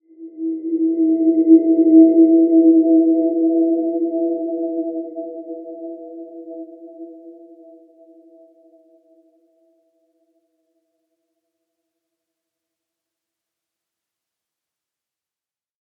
Dreamy-Fifths-E4-p.wav